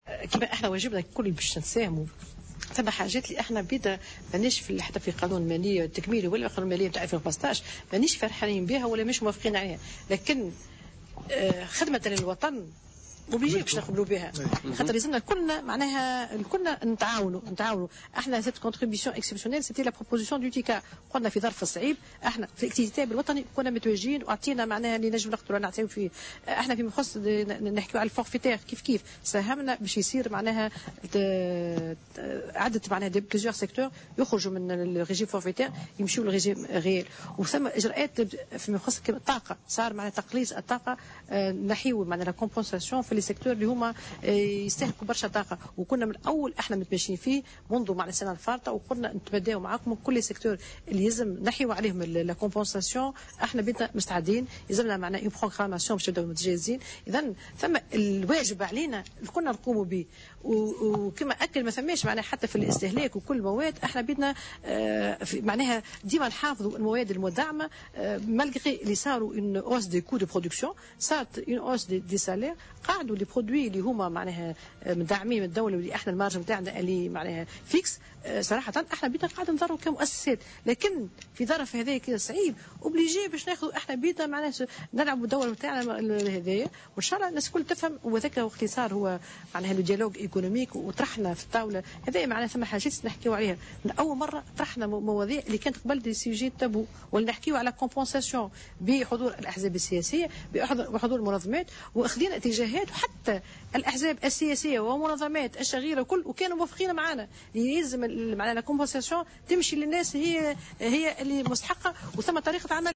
La présidente de la centrale patronale a insisté, lors d'une interwiew accordée à Jawhara Fm, sur la nécessité d'accélérer l'adoption des mesures économiques pour favoriser un climat propice à l'investissement.